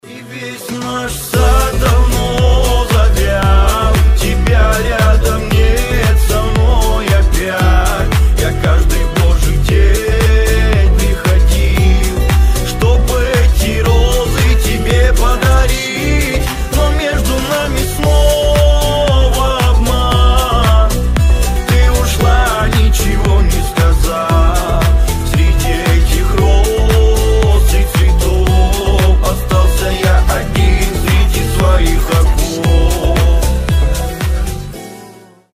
мужской голос
грустные